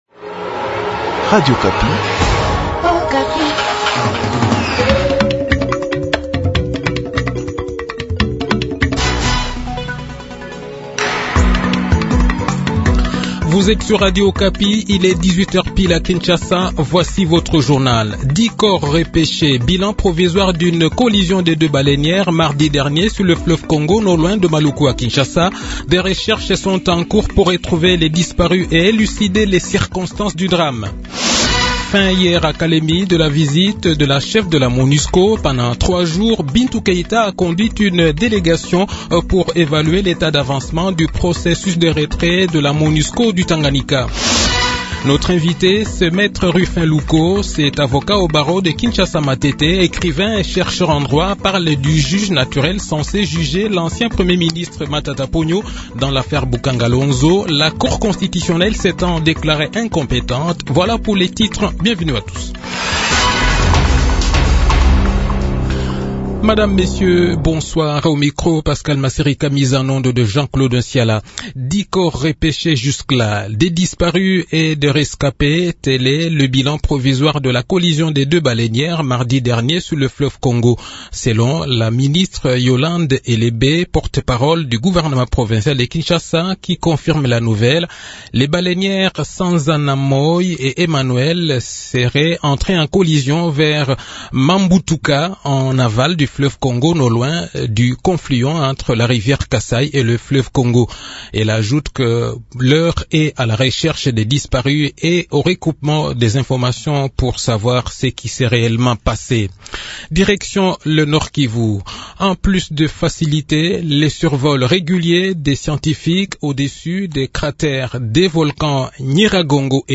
Journal Soir
Le journal de 18 h, 18 Novembre 2021